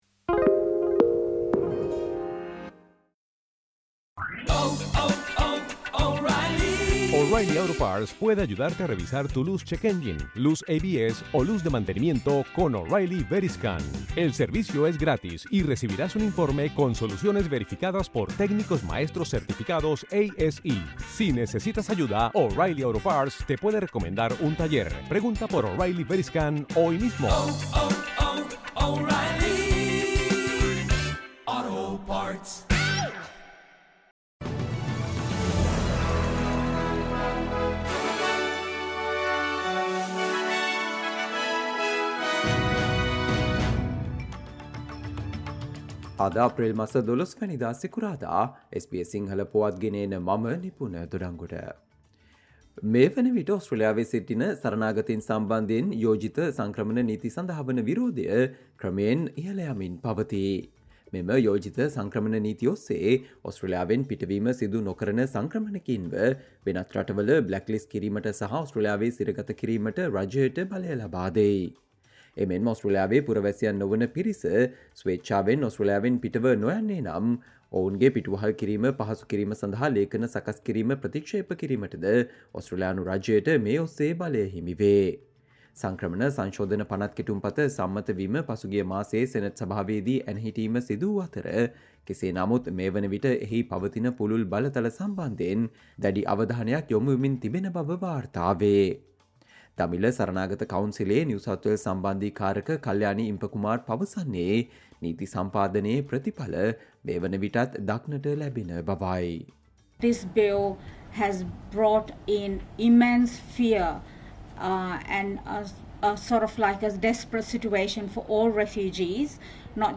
Australia news in Sinhala, foreign and sports news in brief - listen, Friday 12 April 2024 SBS Sinhala Radio News Flash